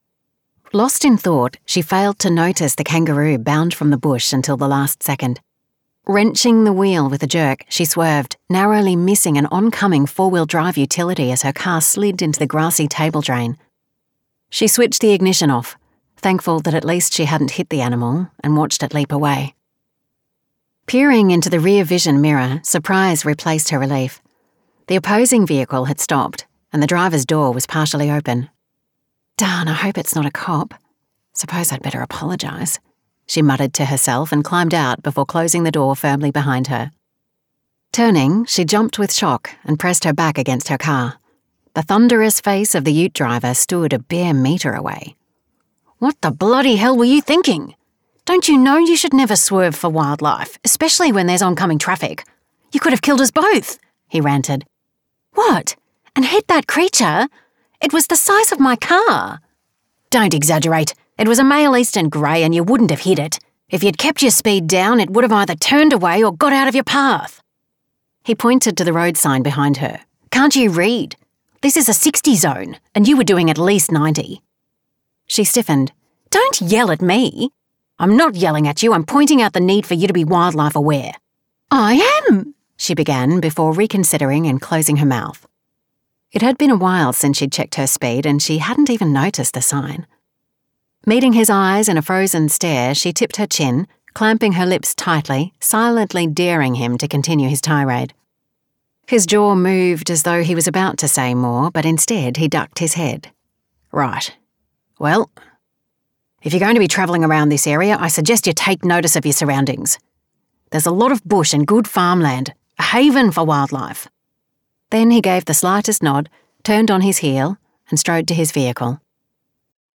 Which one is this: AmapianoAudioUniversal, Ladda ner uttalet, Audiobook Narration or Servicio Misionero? Audiobook Narration